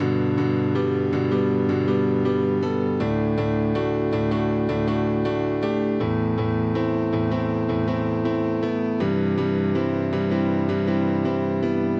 流行钢琴 G大调 慢速
描述：钢琴循环，在Cubase中进行排序。和弦： GGadd9DDsus4AmAsus2CCmaj7
Tag: 80 bpm Pop Loops Piano Loops 2.02 MB wav Key : G